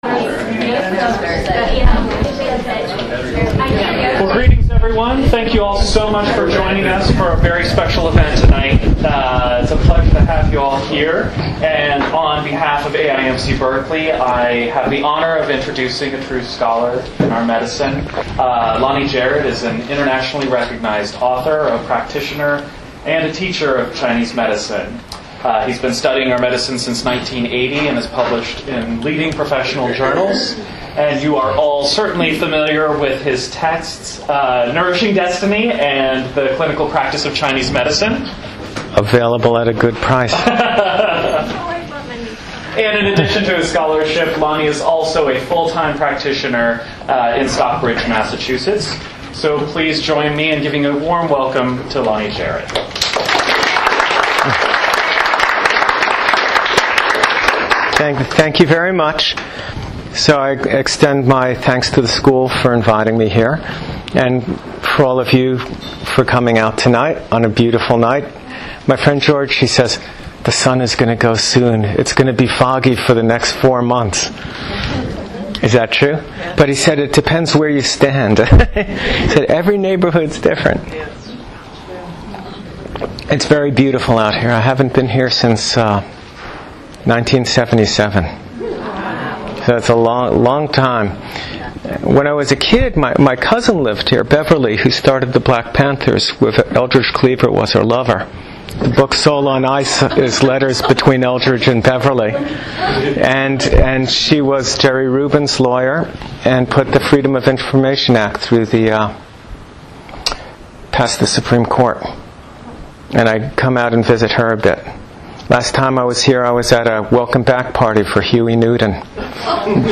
This lecture was to the students and community at AIMC. The crowd was receptive and we had a good time.